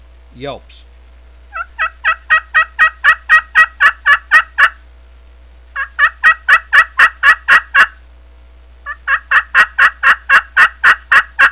High-Frequency Glass Call